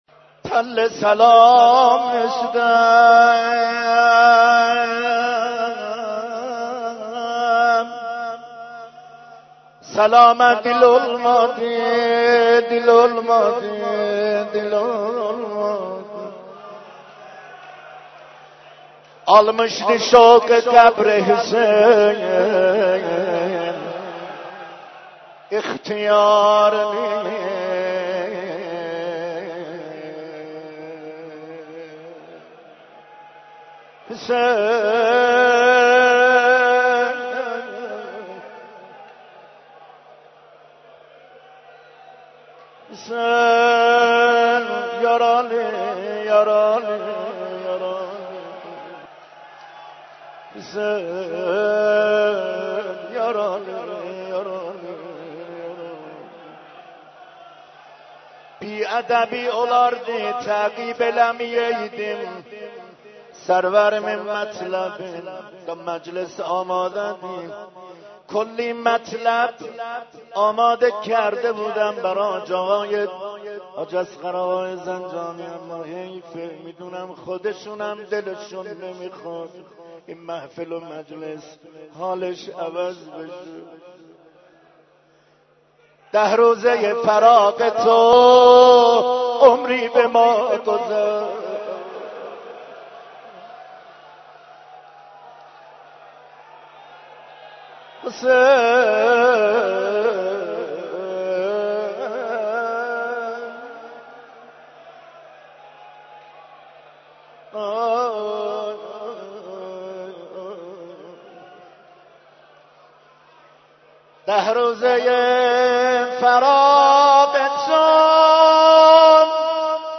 مجموعه مداحی های آیین تجلیل
در حسینیه اعظم زنجان